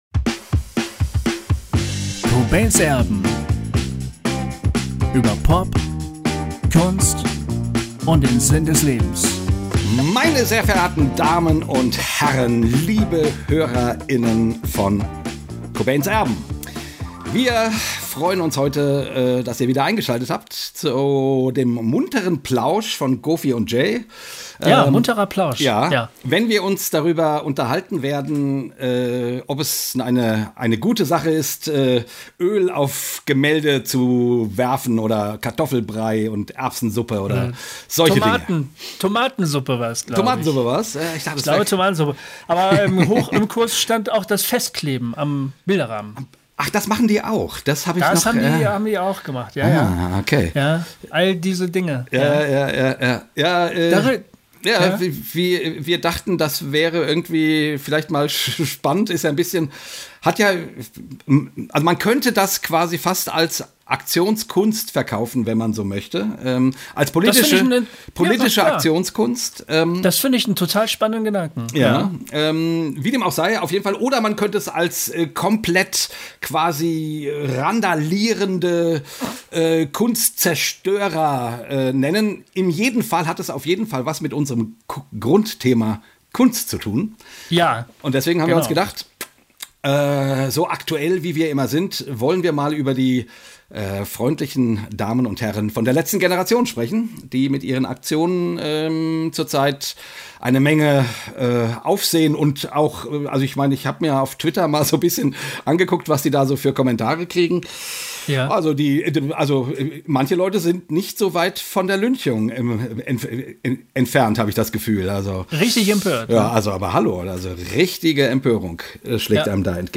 Wir fragen uns in diesem Talk, welche rote Linie er wohl meint?